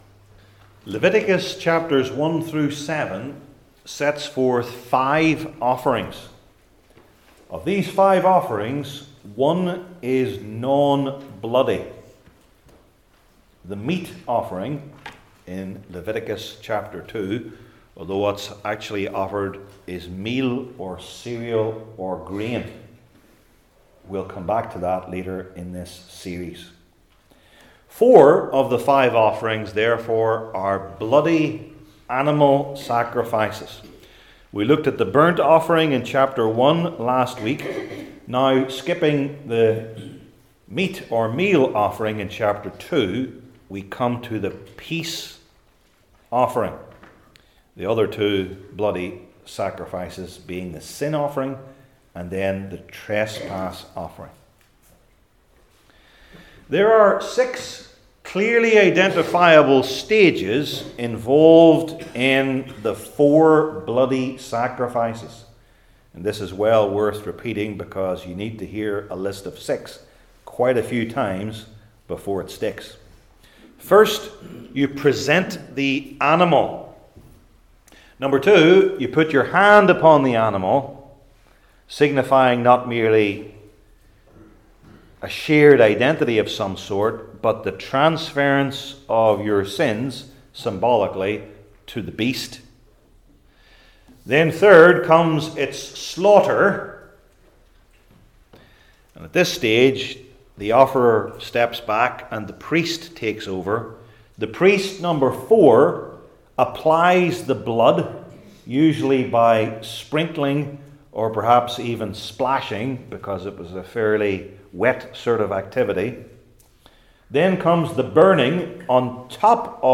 Leviticus 3 Service Type: Old Testament Sermon Series I. Its Distinctiveness Among the Bloody Offerings II.